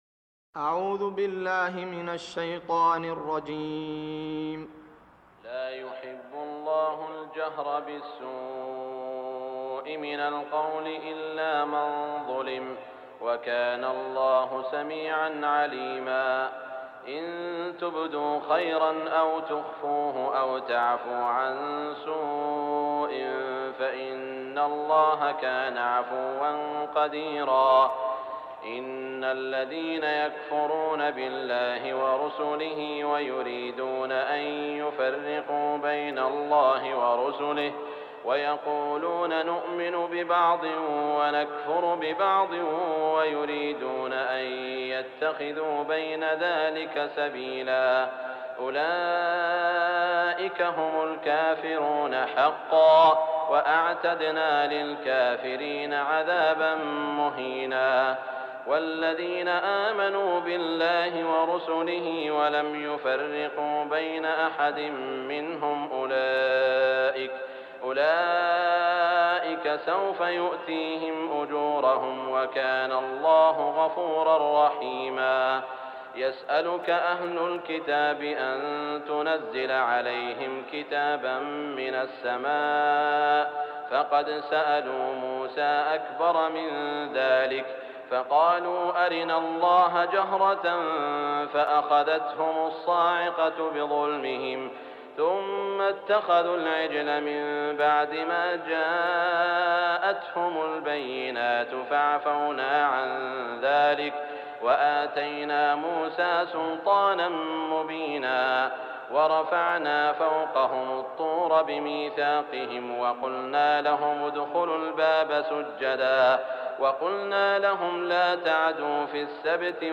تهجد ليلة 26 رمضان 1418هـ من سورتي النساء (148-176) و المائدة (1-40) Tahajjud 26 st night Ramadan 1418H from Surah An-Nisaa and AlMa'idah > تراويح الحرم المكي عام 1418 🕋 > التراويح - تلاوات الحرمين